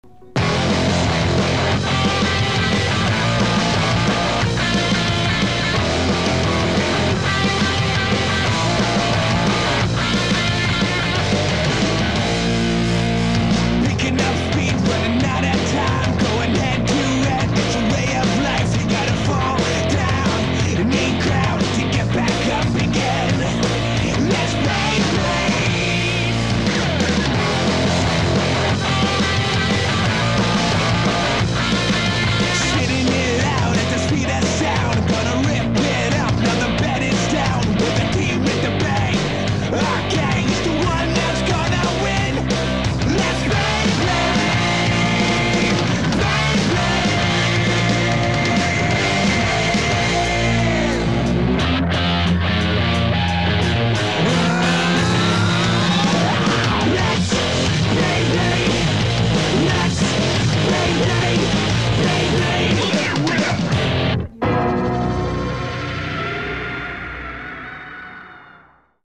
Générique d'opening de la série en Anglais 1min09 (1,05 Mo)